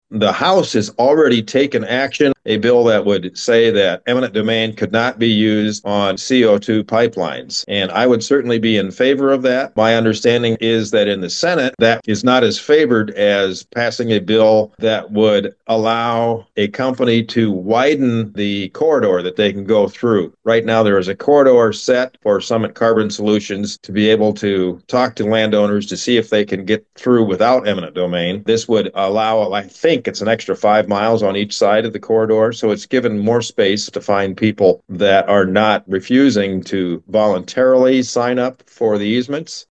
State Senator Dennis Guth of Klemme says the House and Senate are approaching the issue differently, with the House moving to block eminent domain outright while the Senate debates whether changes to the pipeline corridor could offer a compromise.